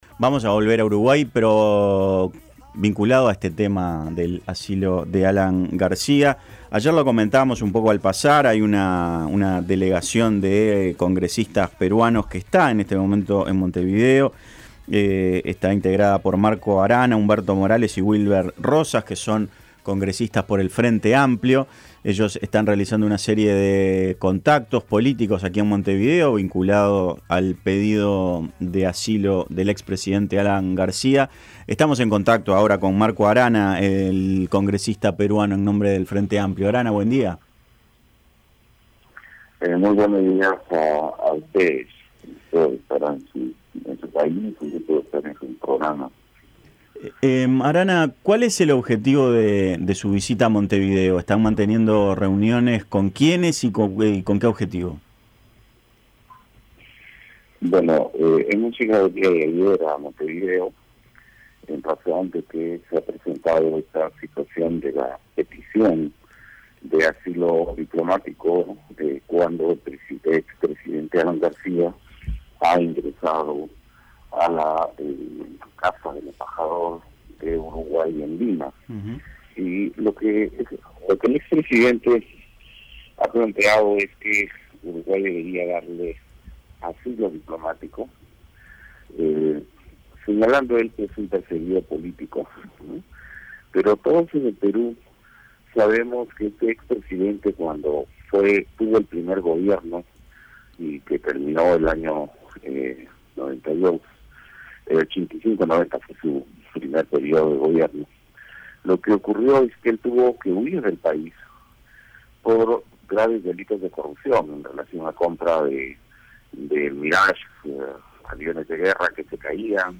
Marco Arana dijo a La Mañana de El Espectador que el objetivo de su visita es demostrar que las explicaciones que dio García en la carta que envió al presidente Tabaré Vázquez son falsos, y aseguró que no es un perseguido político.